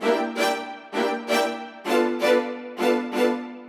Index of /musicradar/gangster-sting-samples/130bpm Loops
GS_Viols_130-EB.wav